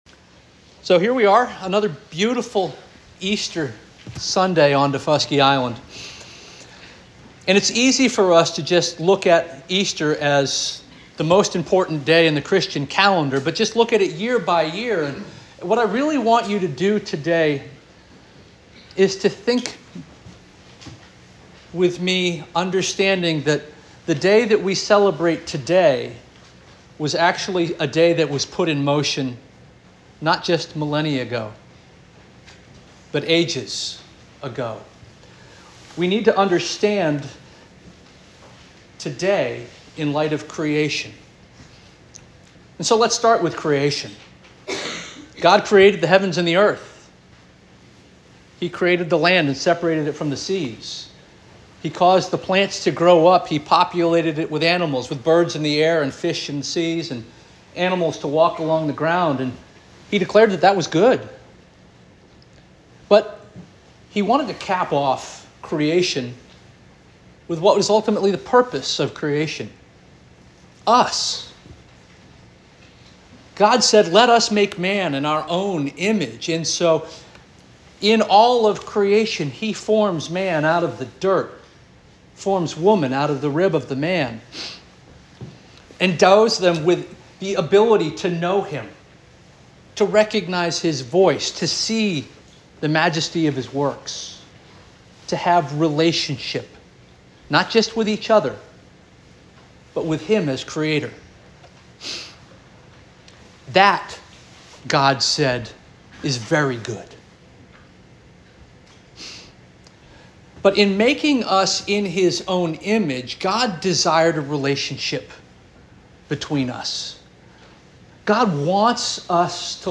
Easter 2025 Sermon